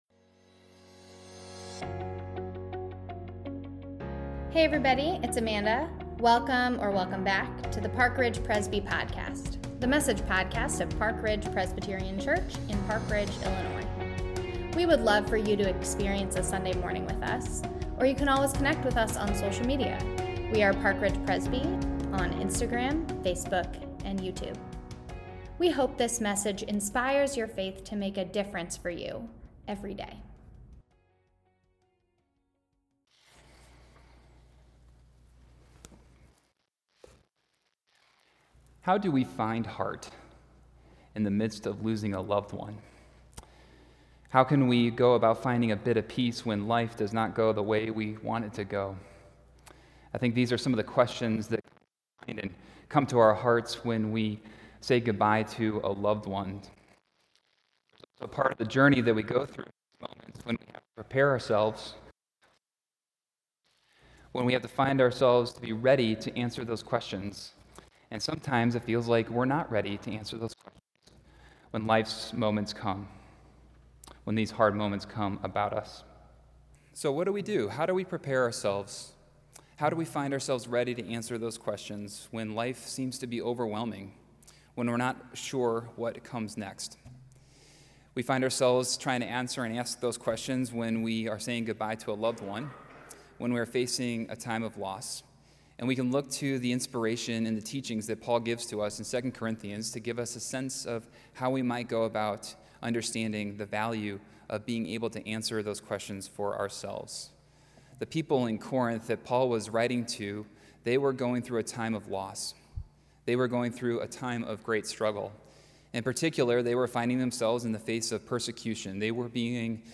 November 3, 2019 – Service of Remembrance 2019 – Park Ridge Presbyterian Church